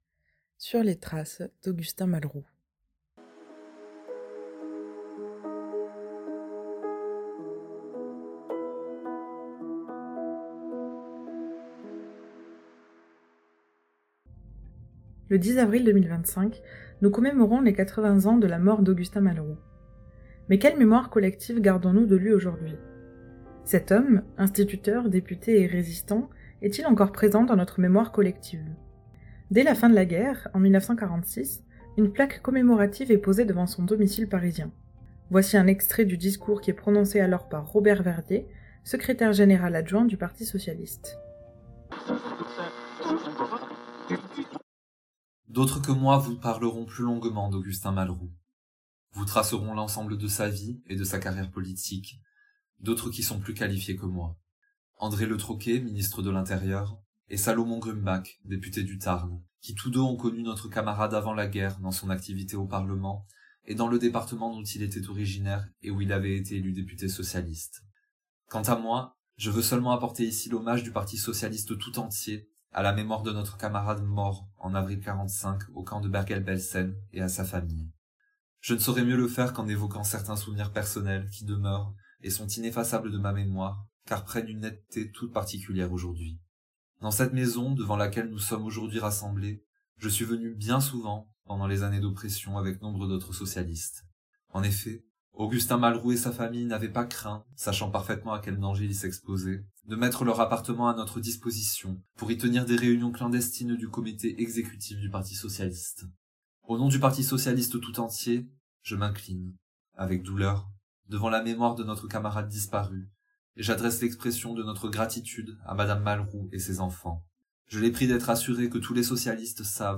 Comédien.